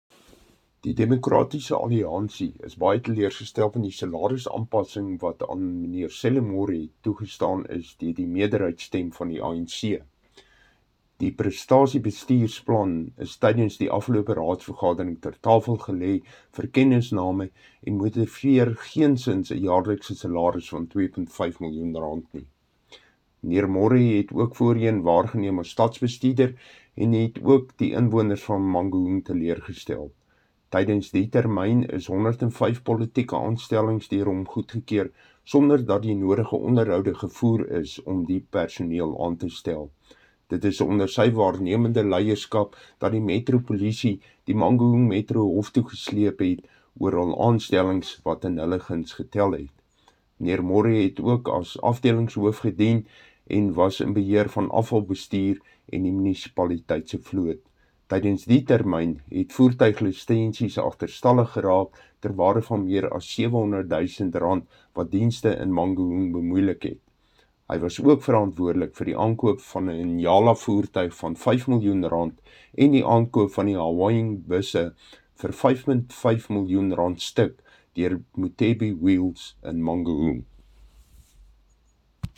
Afrikaans soundbites by Cllr Dirk Kotze and Sesotho by Cllr David Masoeu.
Afr-voice-6.mp3